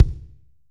B.B KICK 8.wav